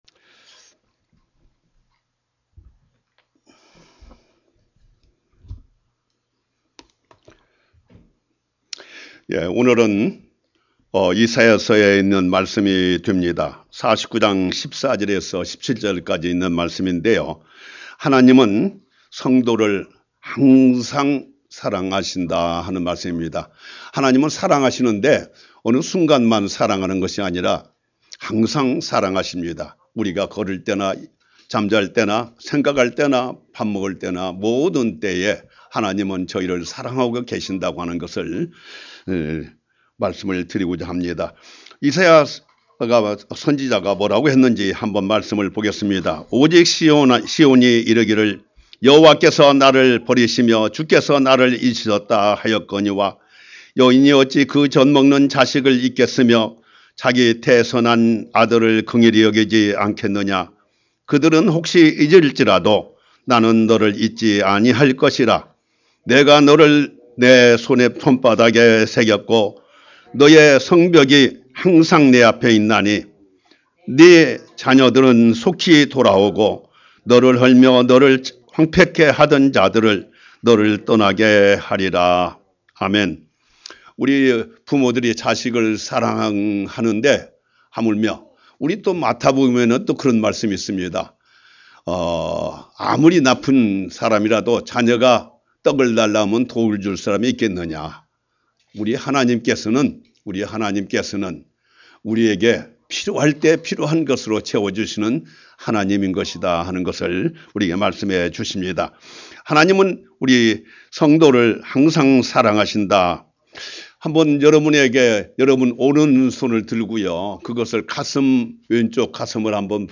Sermon - 하나님은 성도를 항상 사랑하십니다 God loves you always